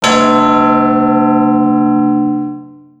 strongman_bell.wav